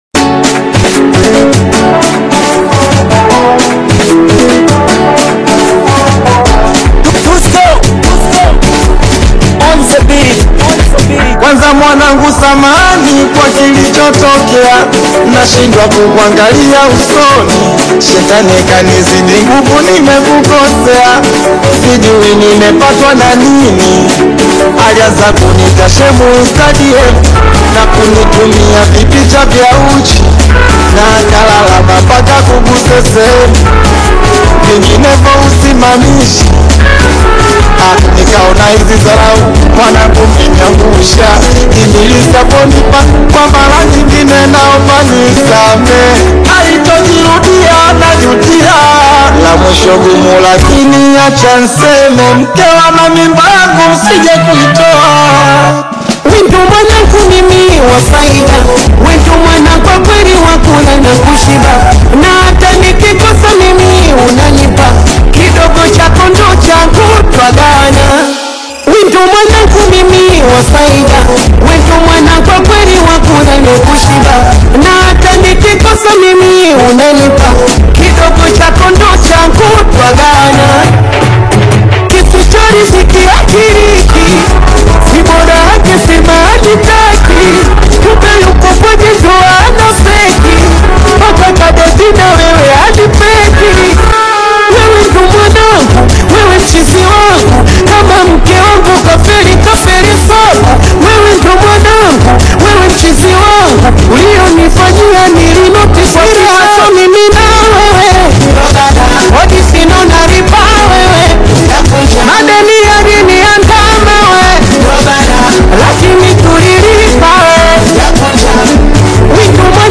heartfelt Bongo Flava single